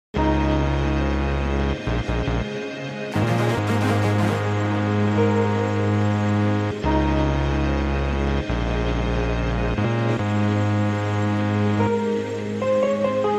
Amazing 750s passing through Manchester sound effects free download